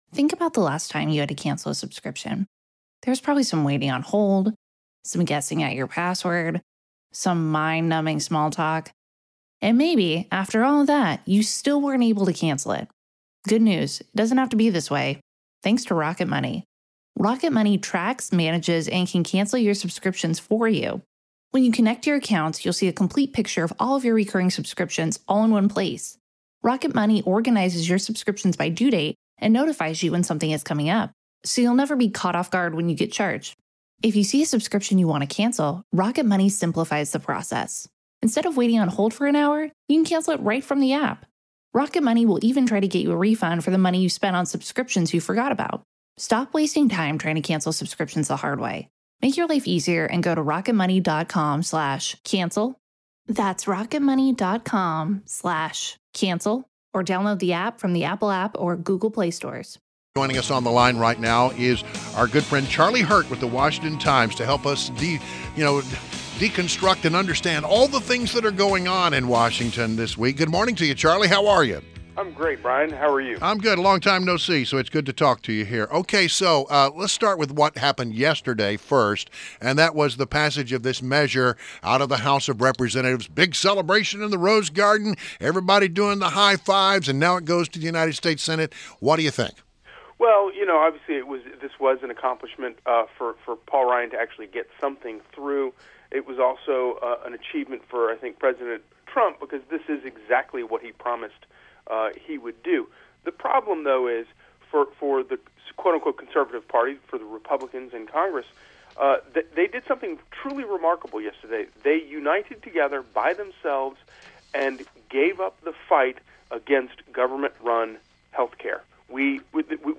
INTERVIEW – CHARLIE HURT – Washington Times